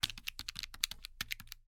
Звуки калькулятора
Звук нажатия кнопок